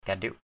/ka-ɗɪʊp/ ~ /ka-ɗɪp/